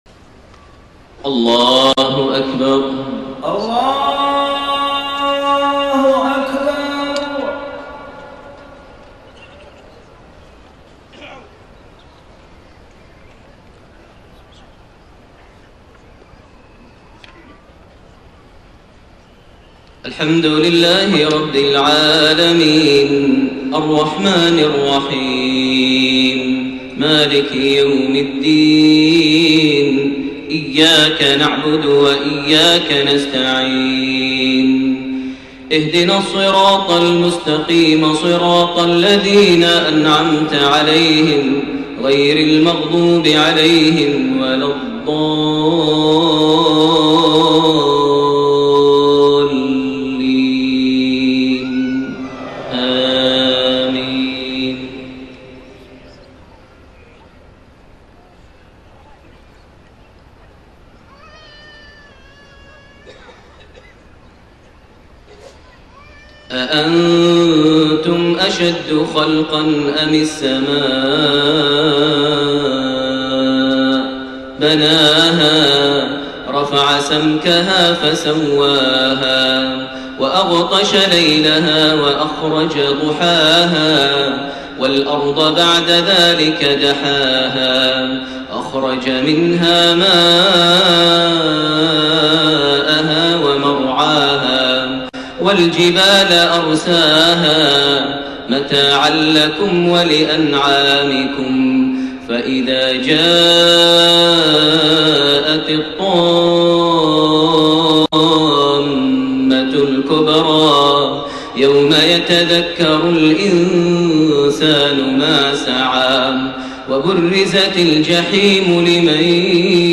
صلاة المغرب8-1-1431 من سورة النازعات27-46 > 1431 هـ > الفروض - تلاوات ماهر المعيقلي